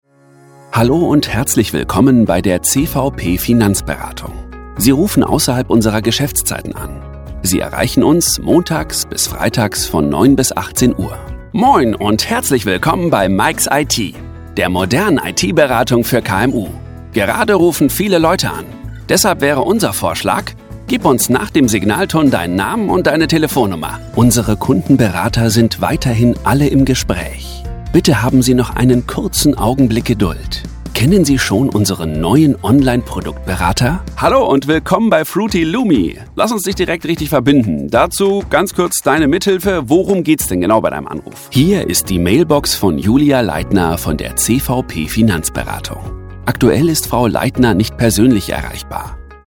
Werbesprecher